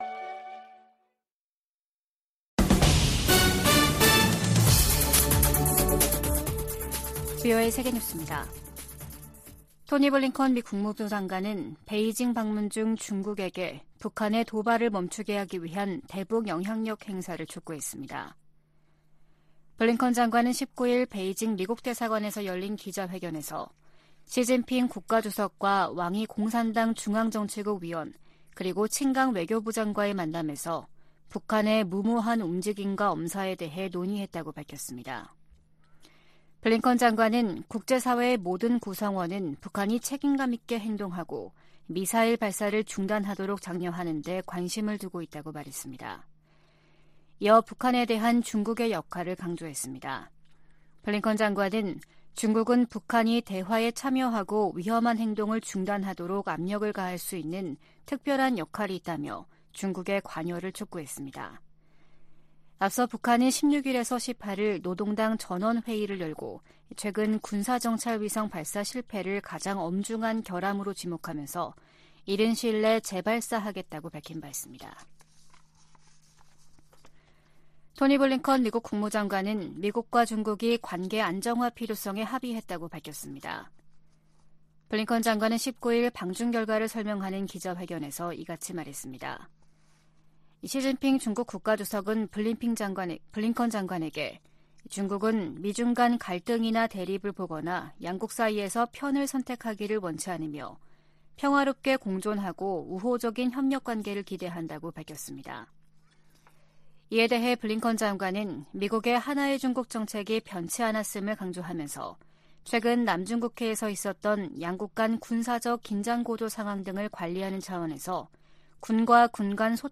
VOA 한국어 아침 뉴스 프로그램 '워싱턴 뉴스 광장' 2023년 6월 20일 방송입니다. 미국의 핵 추진 순항미사일 잠수함의 한국 입항과 관련해 미국 내 전문가들은 이를 미국이 방위 공약을 확인하는 조치로 해석했습니다. 북한은 노동당 전원회의에서 군사정찰위성 발사 실패를 가장 엄중한 결함으로 지목하고 이른 시일 내 재발사하겠다고 밝혔습니다. 미국 하원의장이 주한 중국대사의 이른바 ‘베팅 발언’에 대해 잘못된 것이라고 지적했습니다.